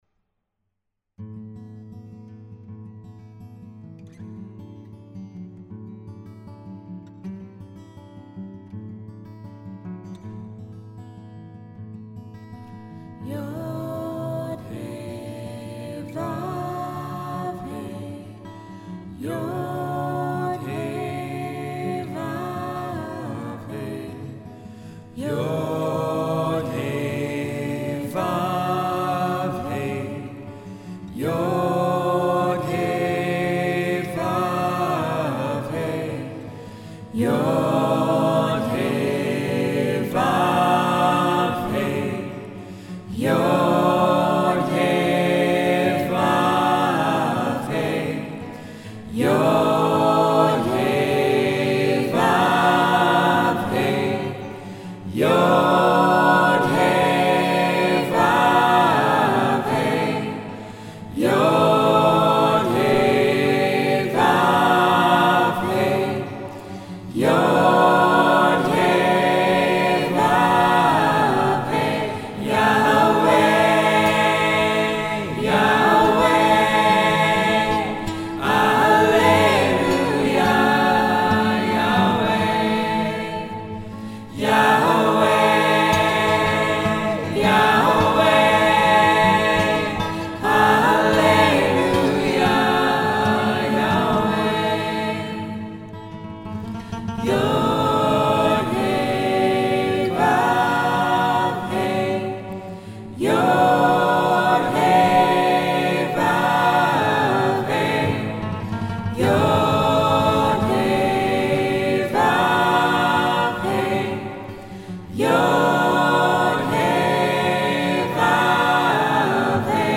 Tetragrammaton singalong song